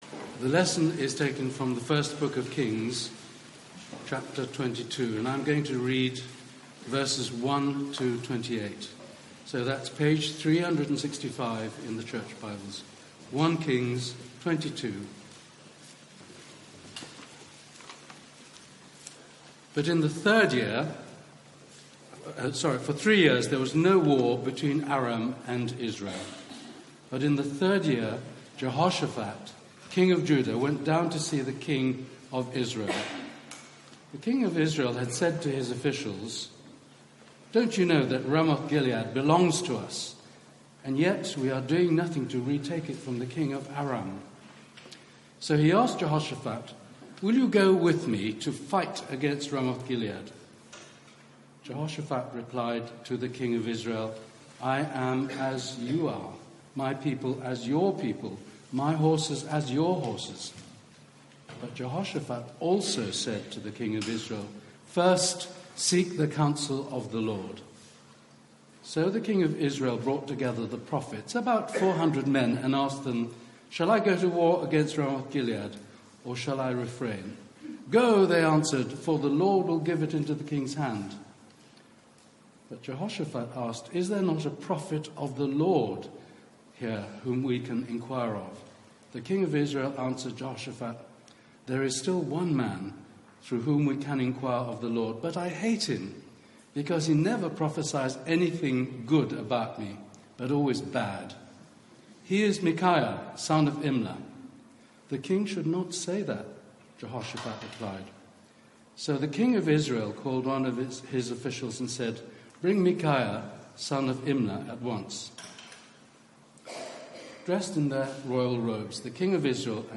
Media for 9:15am Service on Sun 25th Mar 2018
Sermon